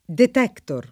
detector [ingl. dit$ktë] s. m. (in it.) tecn.; pl. (ingl.) detectors [dit$ktë@] — anglicismo per «rivelatore» — freq. in It. la pn. [
det$ktor], corrispondente all’etimo lat. della voce ingl. (detector -oris, propr. «scopritore»); ma pn. ingl. nelle locuz. lie detector, metal detector — it. detettore [detett1re]